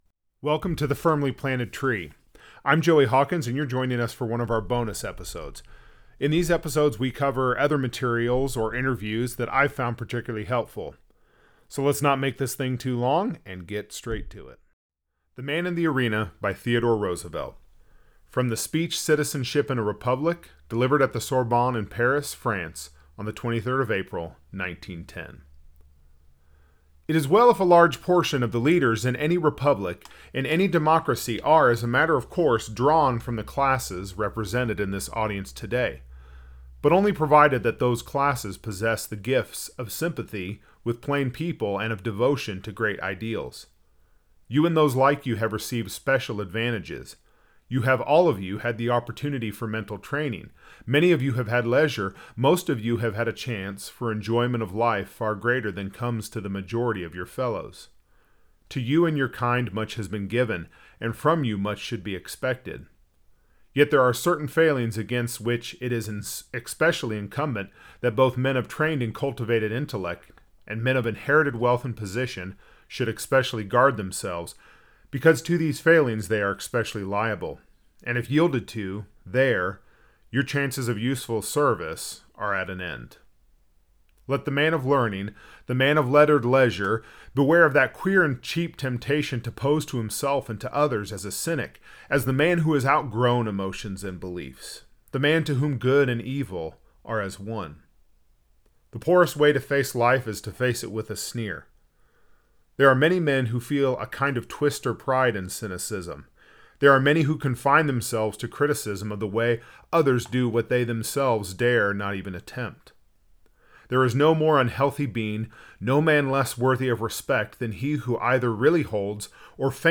A bonus episode of the famous “Man In The Arena” by Theodore Roosevelt. It comes from his speech, “Citizenship In A Republic” delivered at the Sorbonne in Paris, France on the 23rd of April 1910. I hope you enjoy the reading and share the podcast with a friend!